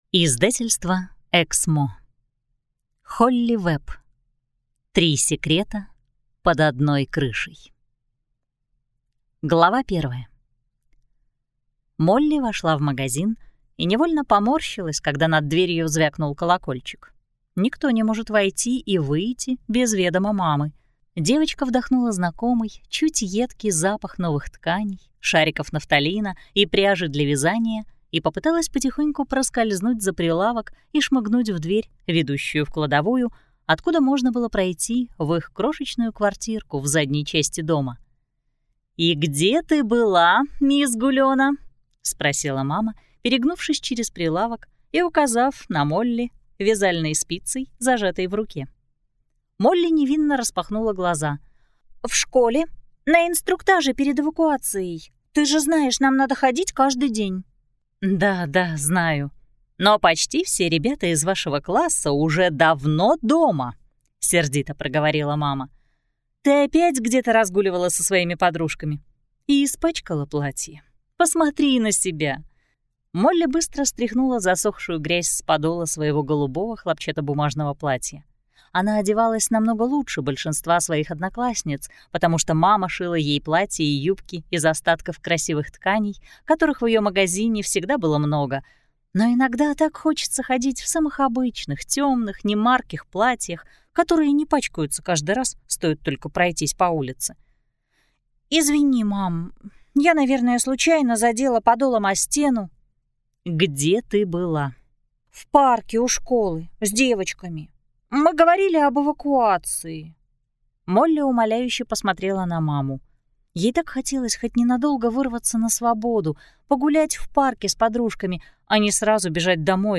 Аудиокнига Три секрета под одной крышей | Библиотека аудиокниг